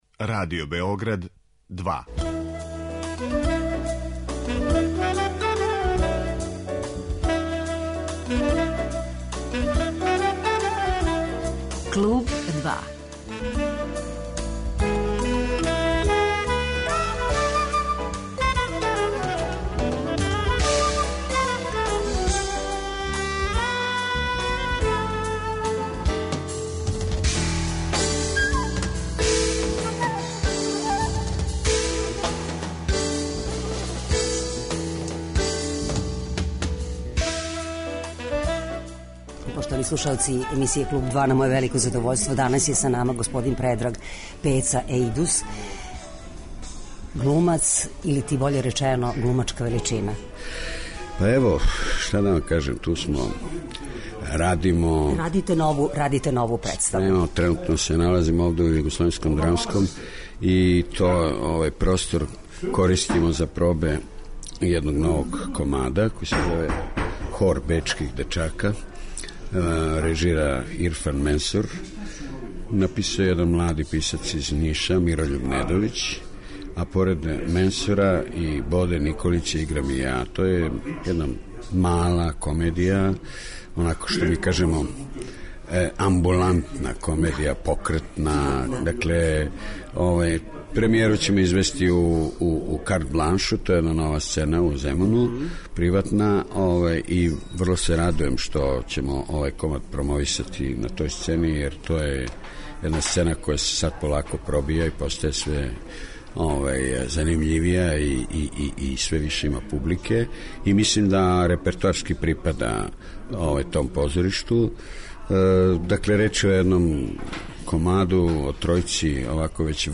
У данашњој емисији слушаћете раговор са драмским уметником Предрагом Ејдусом, о позоришту и новим улогама.